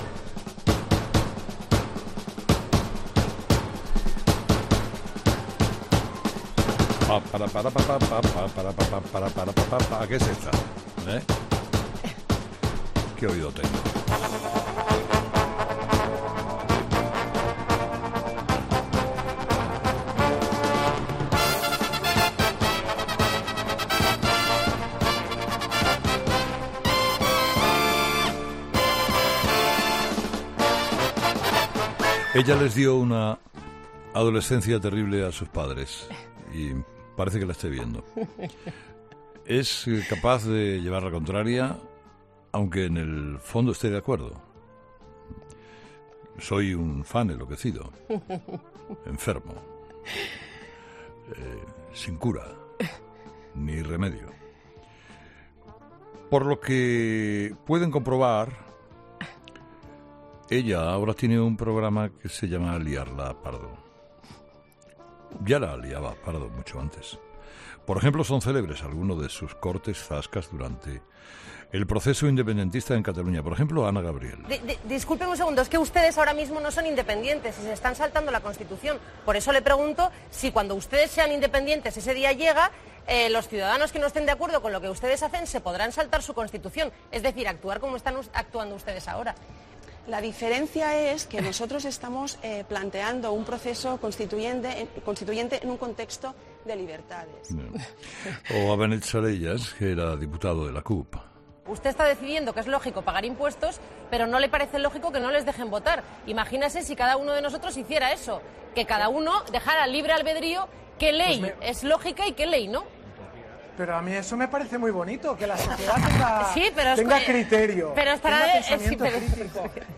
Escucha la entrevista a Cristina Pardo en Herrera en COPE